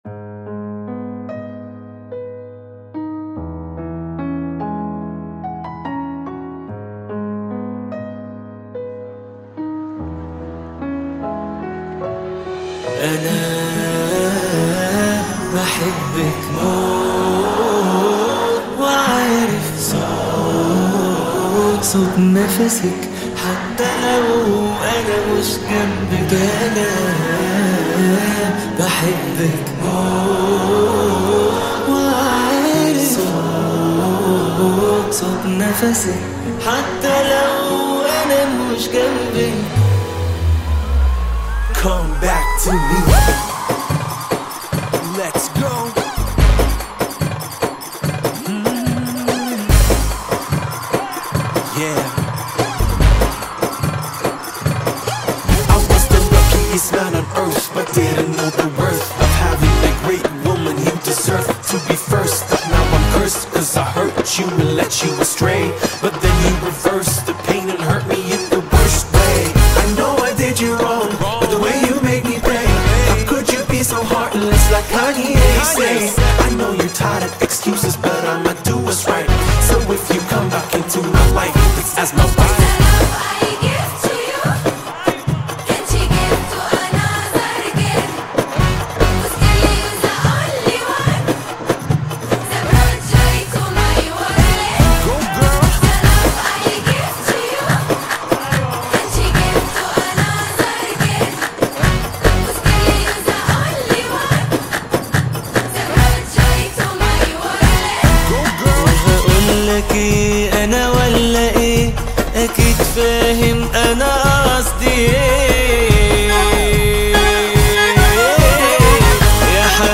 ريمكس جامد اوى